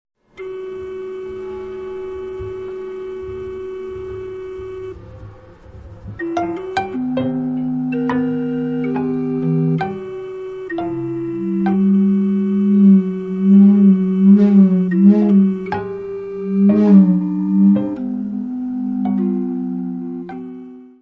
barrel organ
piano, prepared piano
tuba, serpent
double bass
percussion
Music Category/Genre:  New Musical Tendencies